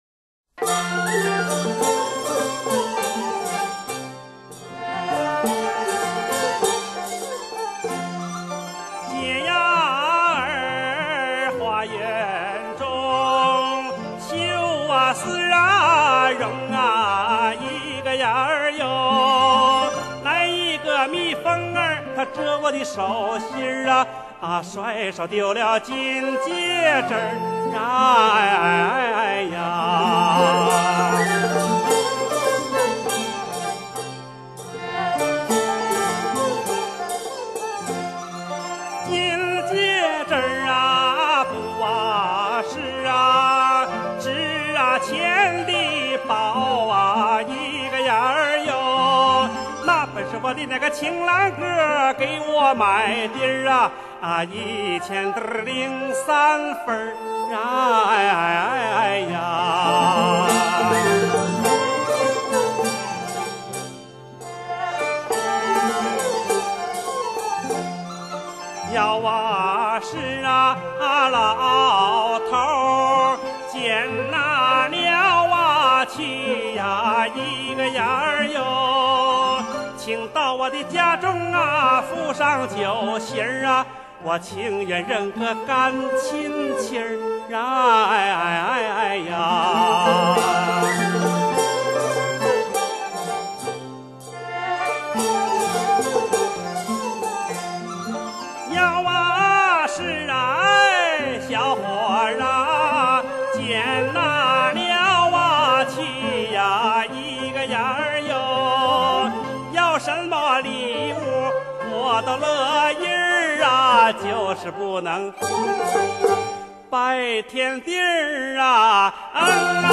音乐风格: 民谣